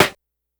snr_17.wav